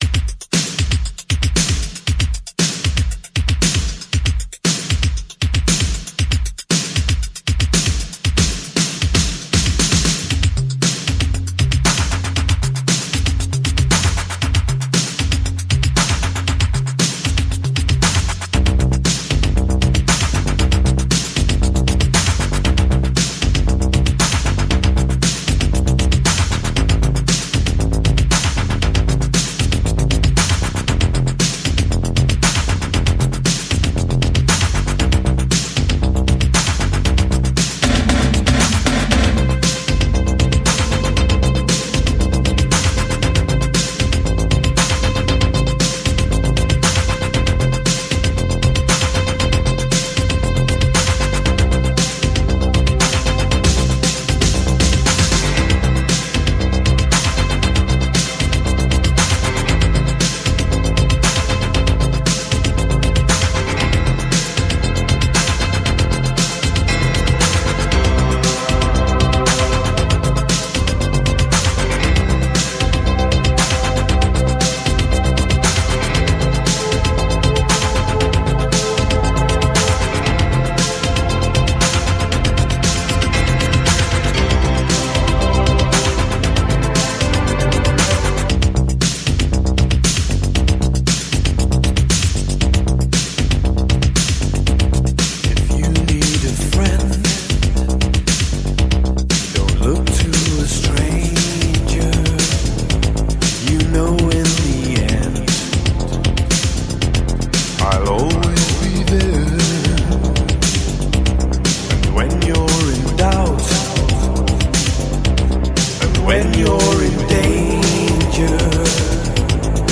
Типа Indie Pop, New Wave.